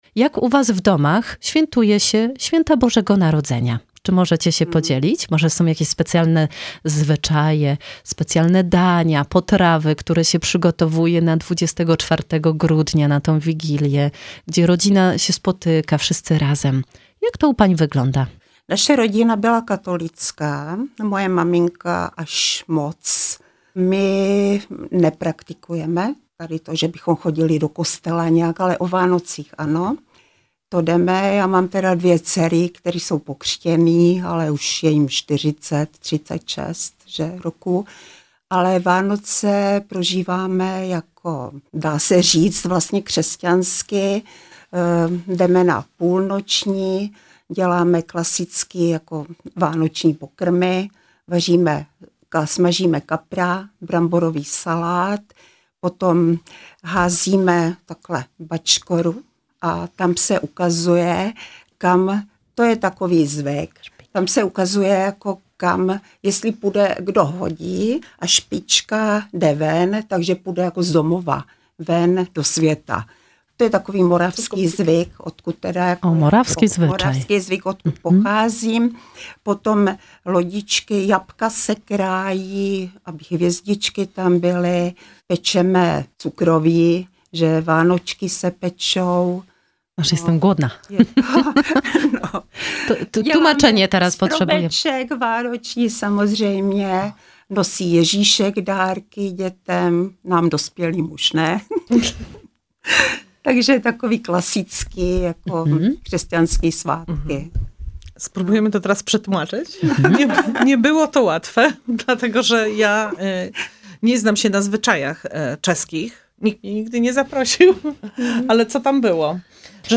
Panie poznały miasto i Ostrów Tumski, opowiadając o zwyczajach, kolędach Bożego Narodzenia oraz świątecznej kuchni prosto z Moraw!